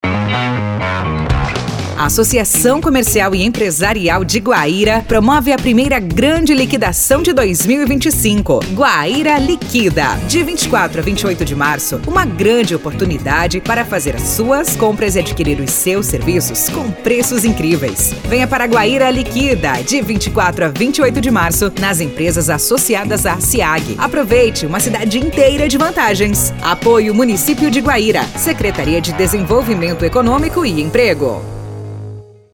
Spot Rádio Campanha
spot_guaira_liquida_2025.mp3